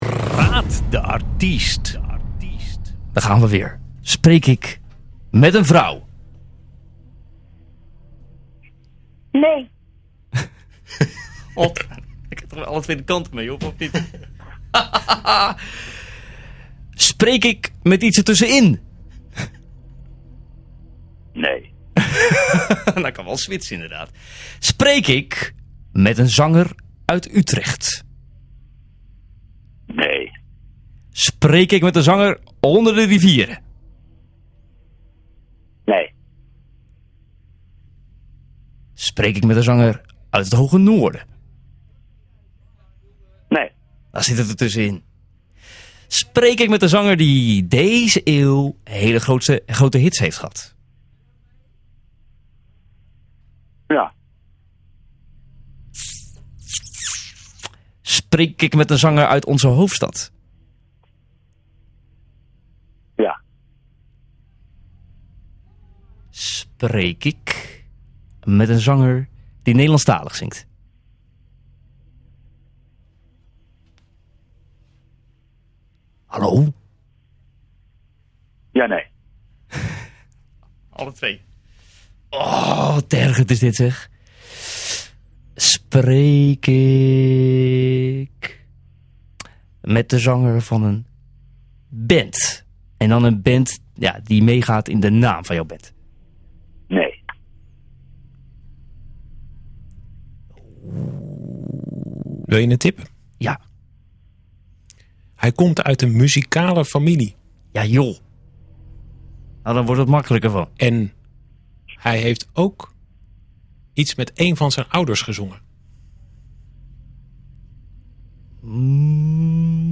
moest raden wie hij aan de lijn had